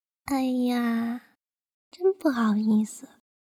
SFX害羞1音效下载
SFX音效